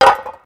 18. 18. Percussive FX 17 ZG